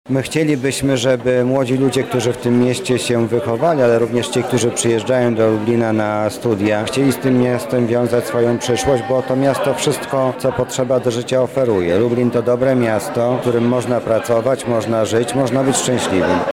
Właśnie takie atrakcje czekały na odwiedzających Akademickie Centrum Kultury i Mediów UMCS Chatka Żaka podczas wydarzenia „Future in Lublin – akademickie miasto przyszłości”.
Lublin to miasto, któremu nie brakuje niczego, oprócz PR-u – mówi Mariusz Banach, zastępca prezydenta Lublina ds. oświaty i wychowania: